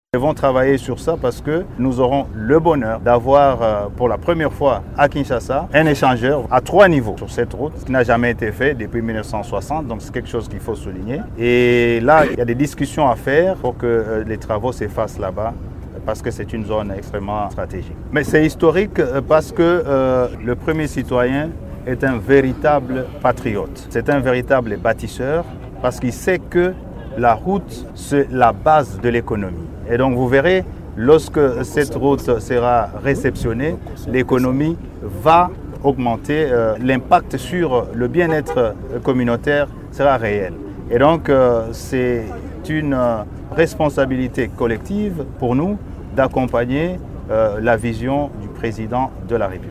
Suivez les propos du ministre Banza à l'issue de sa visite: